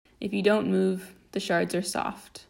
A segment of the "Spoken Text" audio file, specifically the phrase "If you don't move, the shards are soft". No further audio effects were added.
Edited with and exported from Abletone Live.